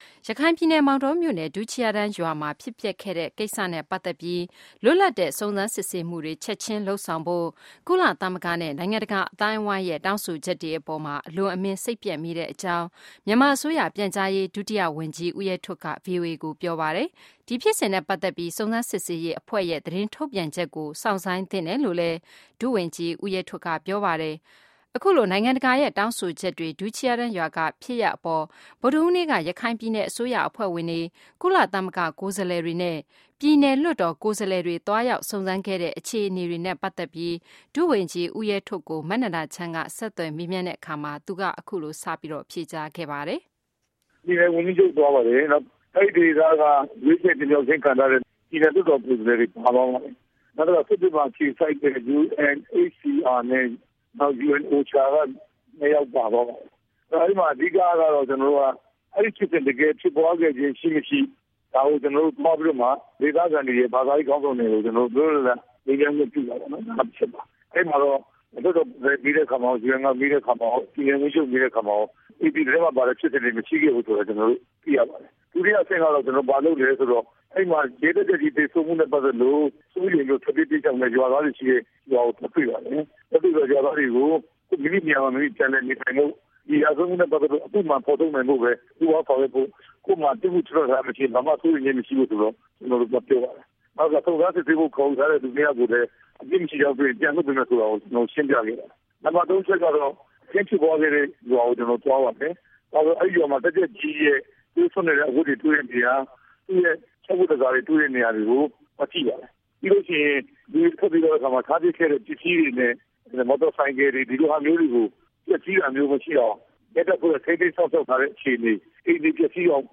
ဦးရဲထွဋ် နဲ့ VOA အမေး၊ အဖြေ အင်တာဗျူး